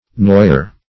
noyer - definition of noyer - synonyms, pronunciation, spelling from Free Dictionary Search Result for " noyer" : The Collaborative International Dictionary of English v.0.48: Noyer \Noy"er\, n. An annoyer.